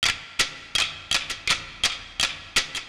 The Sticks Loop.wav